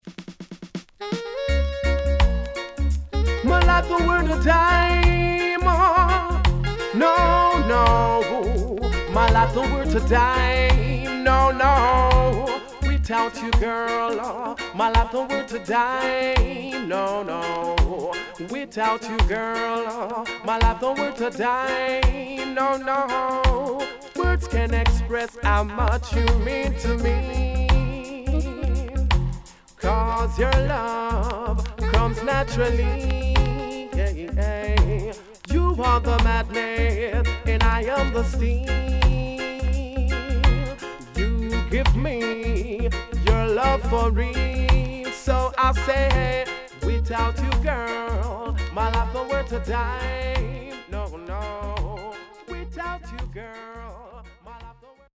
HOME > Back Order [DANCEHALL LP]
SIDE B:所々チリノイズがあり、少しプチパチノイズ入ります。